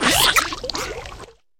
Cri de Canarbello dans Pokémon HOME.